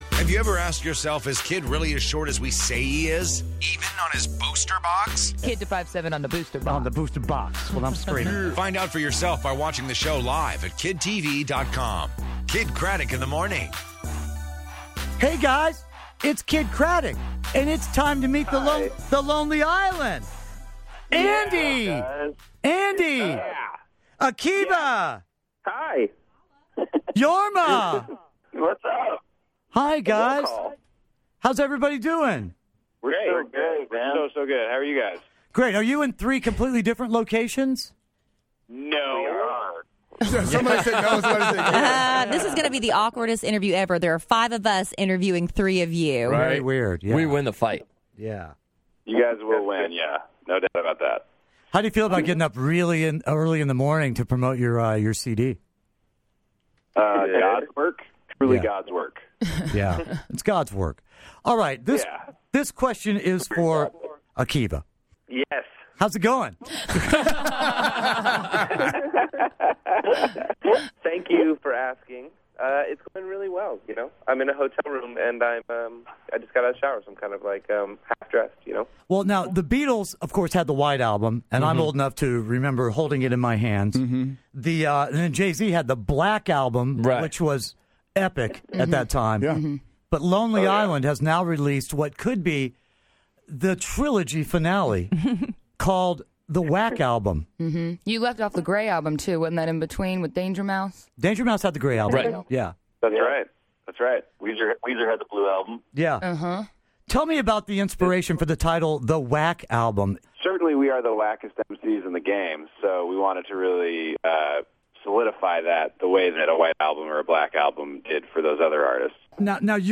Kidd Kraddick in the Morning interviews The Lonely Island!